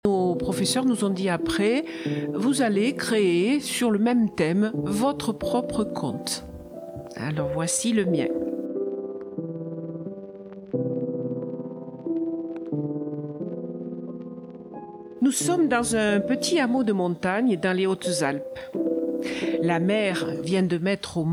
Tous les derniers mercredi du mois, à 18h11, retrouvez le Cercle des conteurs du Briançonnais pour une balade rêveuse.